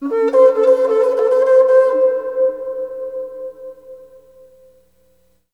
REBKHorn08.wav